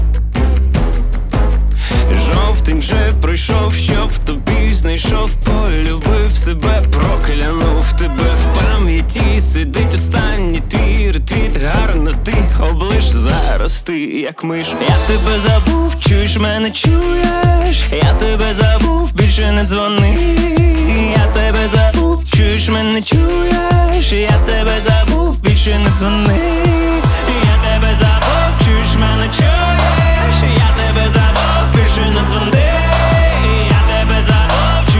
Жанр: Поп / Украинские